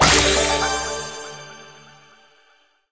brawl_lootegg_open_2.ogg